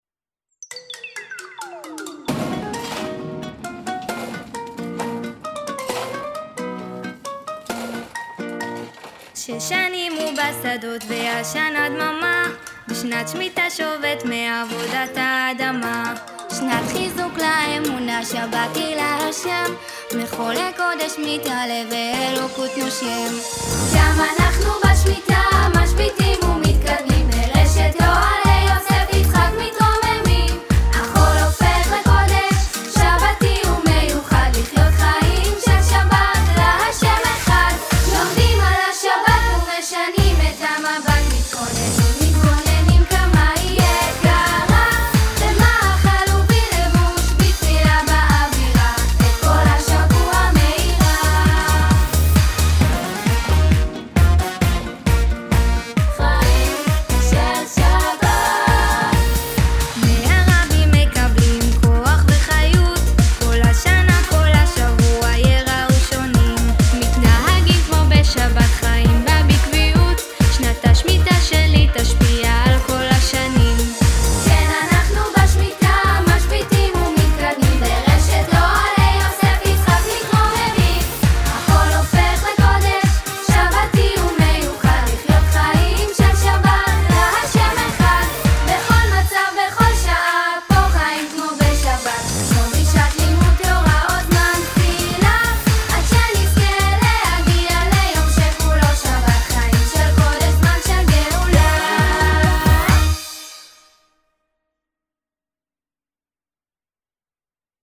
שיר